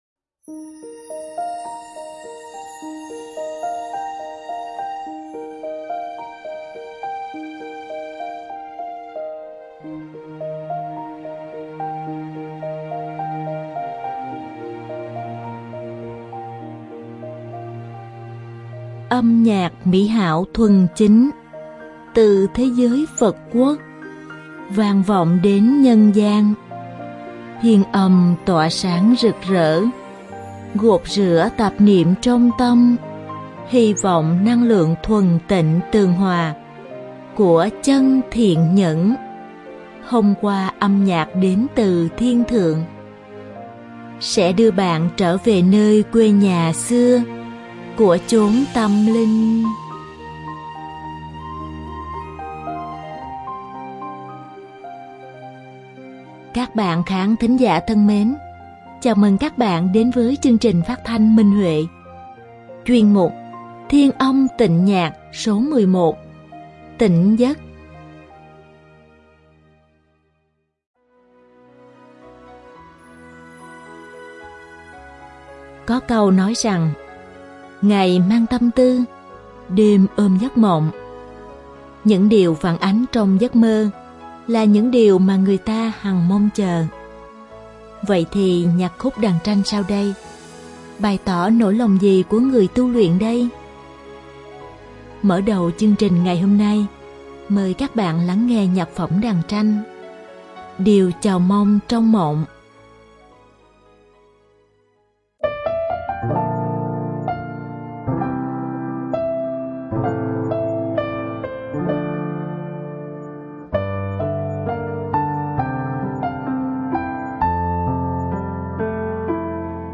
Diễn tấu đàn tranh Việt Nam
Đơn ca nữ
Đơn ca nam